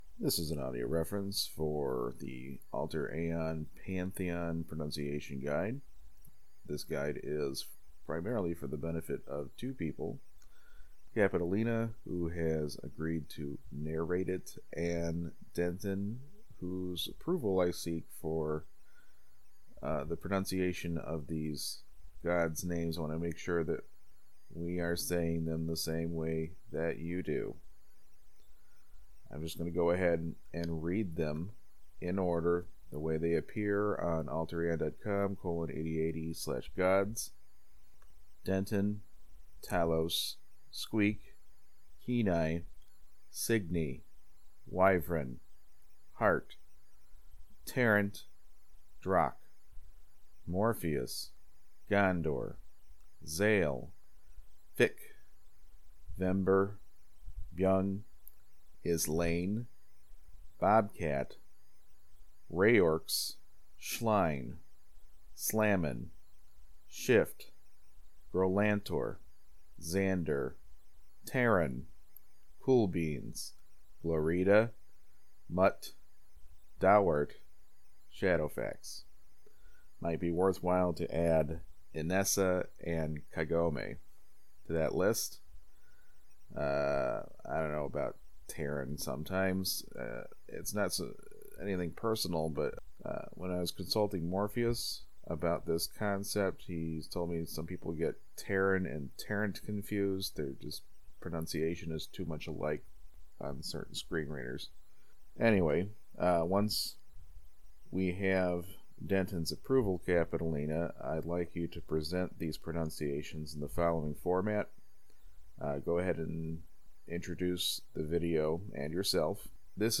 alter_aeon_prounciation_guide_reference.mp3